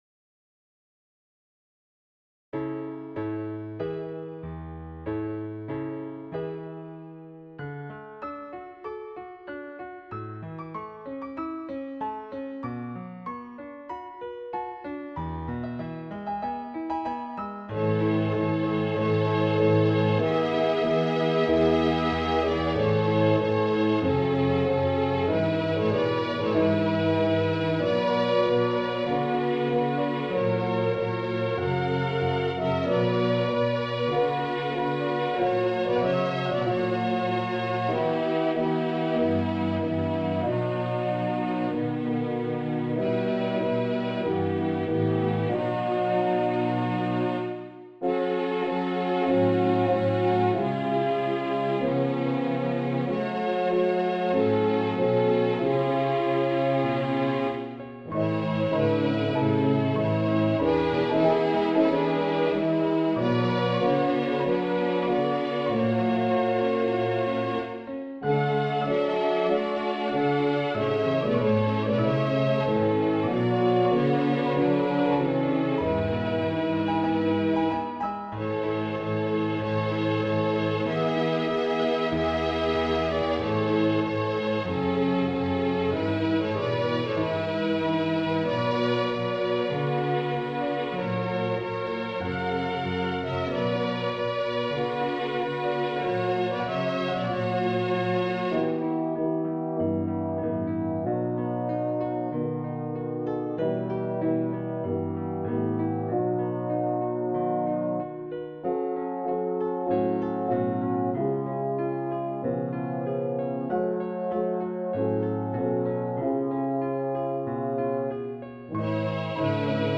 Explorations et Eveil Spirituels : 10 Oeuvres pour Choeur et Piano